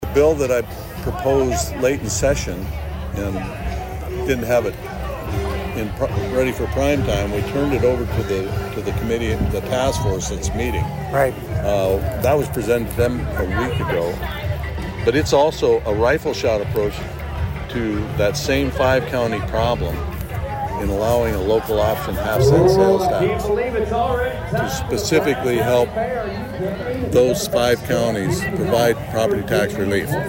Prior to that Governor Rhoden did an interview with HubCityRadio to address a variety of topics.